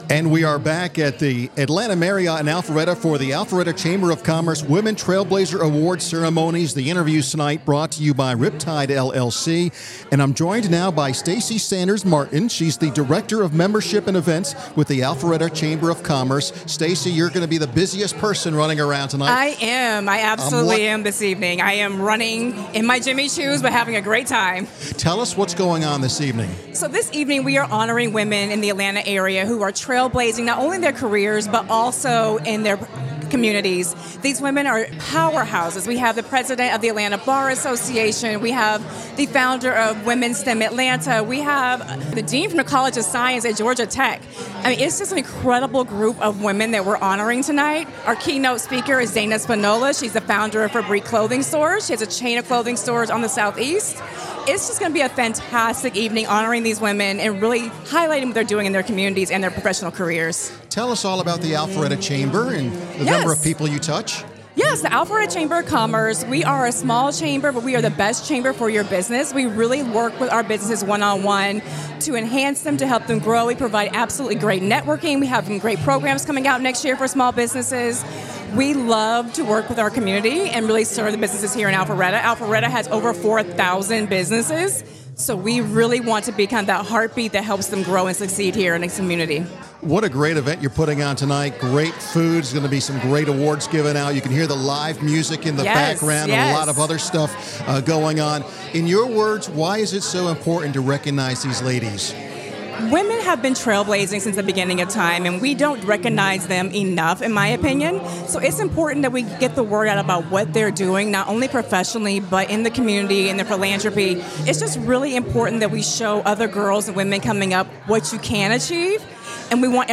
Business RadioX partnered with Riptide, LLC to interview several of the winners and other leaders attending the prestigious event.